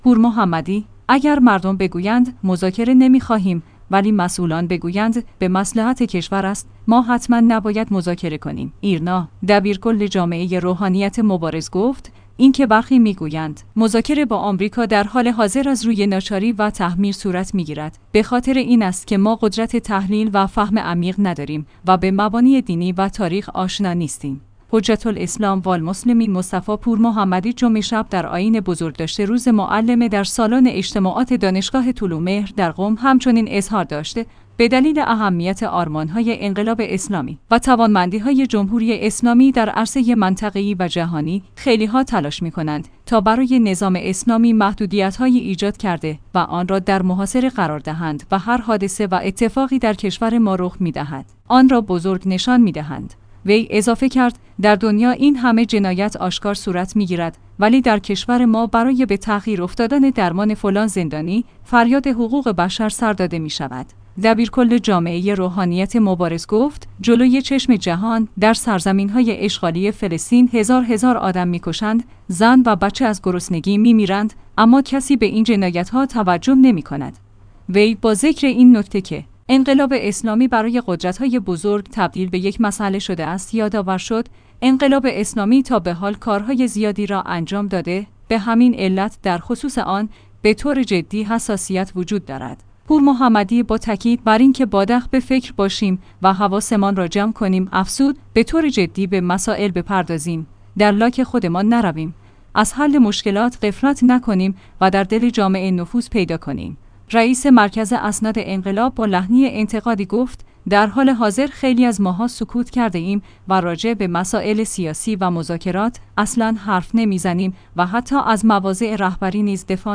حجت‌الاسلام‌والمسلمین مصطفی پورمحمدی جمعه شب در آیین بزرگداشت روز معلم در سالن اجتماعات د